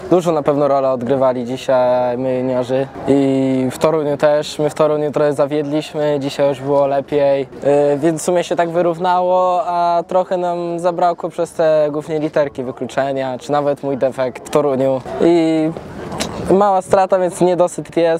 Wypowiedzi po finale: